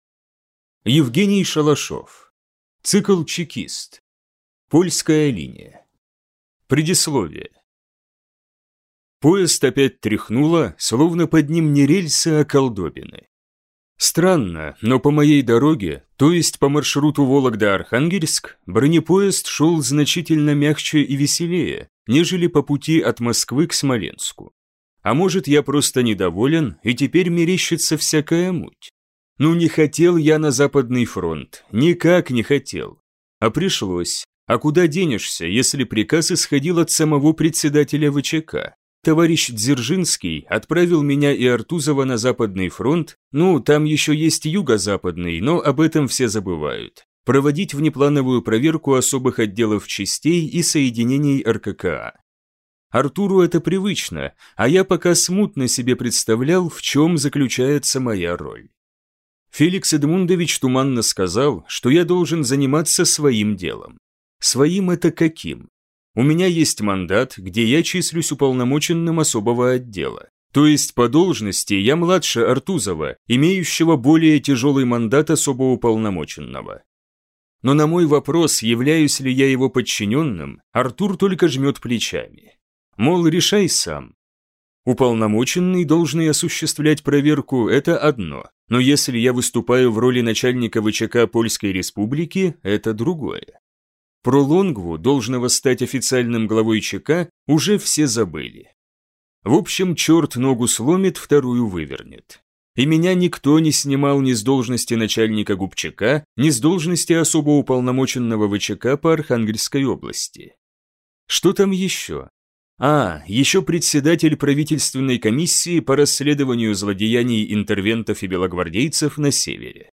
Аудиокнига Чекист. Польская линия | Библиотека аудиокниг